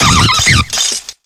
0b9e83f554e04c7e43fbdd6e25d4c1432e2c58ba infinitefusion-e18 / Audio / SE / Cries / CARNIVINE.ogg infinitefusion d3662c3f10 update to latest 6.0 release 2023-11-12 21:45:07 -05:00 14 KiB Raw History